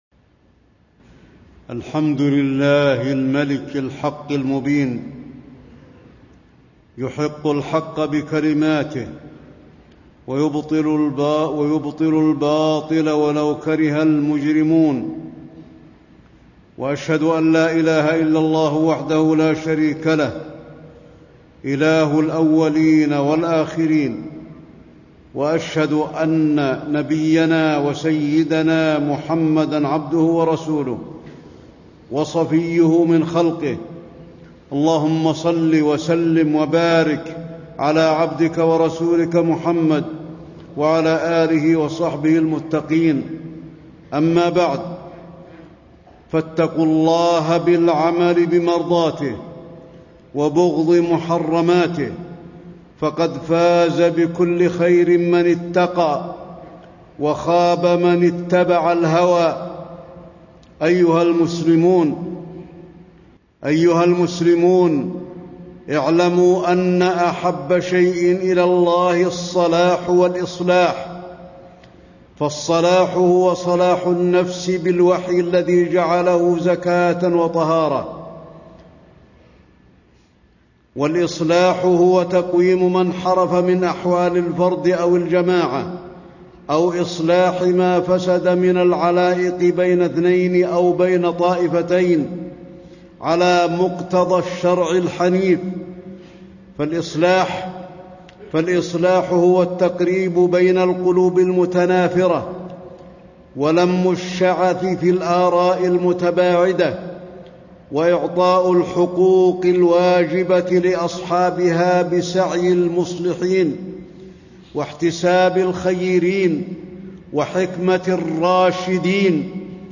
تاريخ النشر ٢٦ شوال ١٤٣٥ هـ المكان: المسجد النبوي الشيخ: فضيلة الشيخ د. علي بن عبدالرحمن الحذيفي فضيلة الشيخ د. علي بن عبدالرحمن الحذيفي فضل الإصلاح والمصلحين